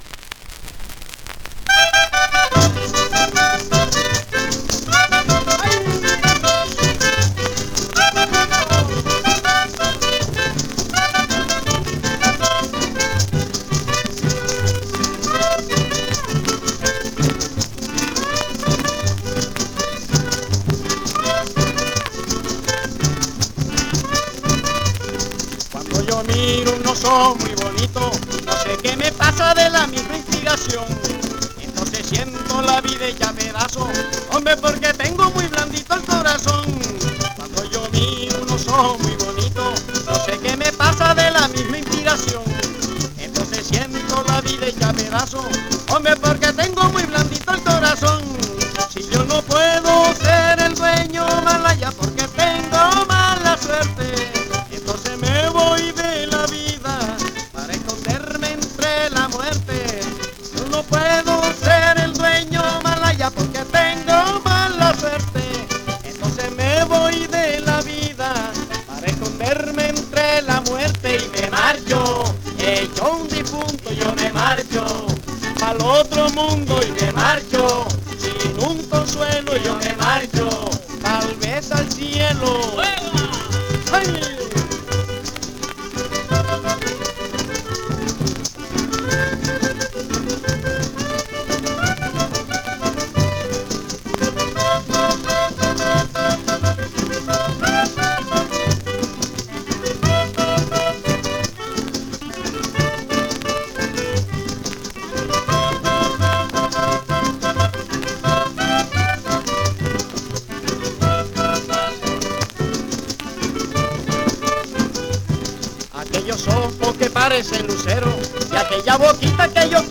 1 disco : 78 rpm ; 25 cm